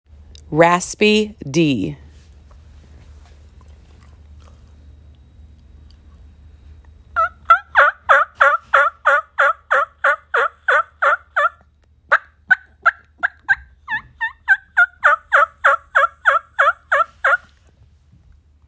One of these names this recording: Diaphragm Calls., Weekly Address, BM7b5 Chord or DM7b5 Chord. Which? Diaphragm Calls.